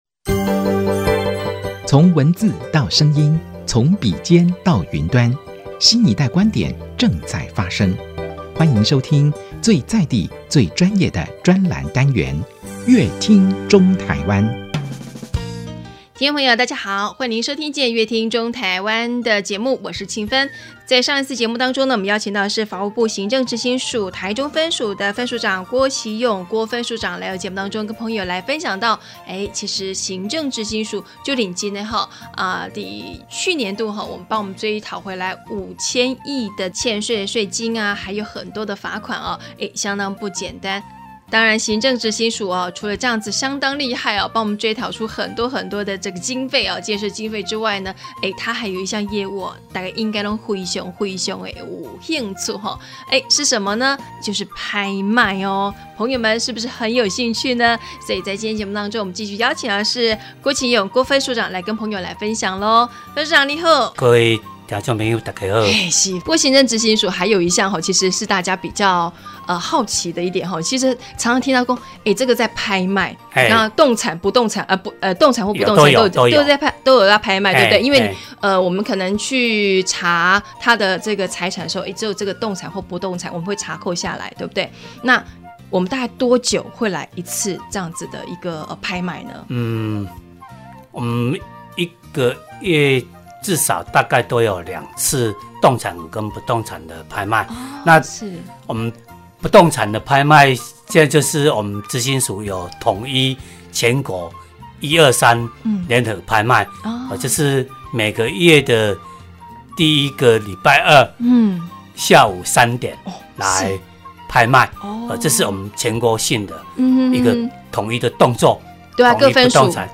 本集來賓：法務部行政執行署臺中分署郭棋湧分署長 本集主題：「搶標拍賣品 行政執行署提供貼心服務」 本集內容：